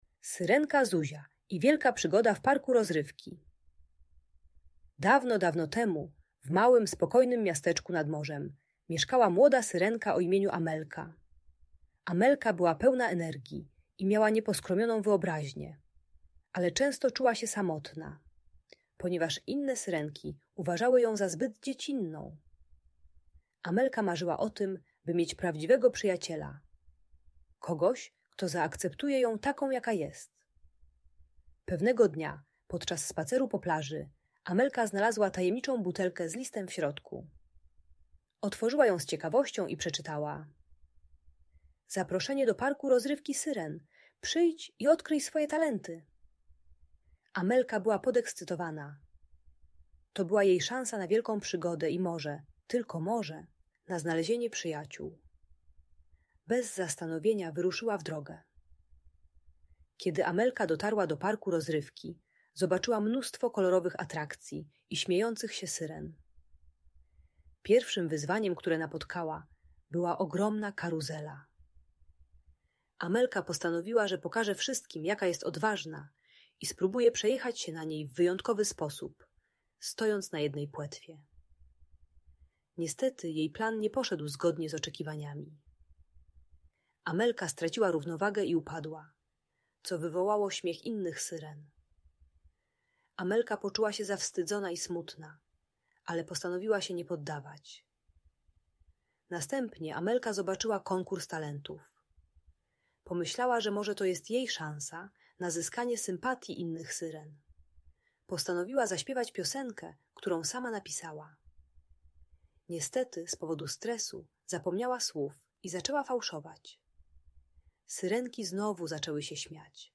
Syrenka Zuzia i Wielka Przygoda - Szkoła | Audiobajka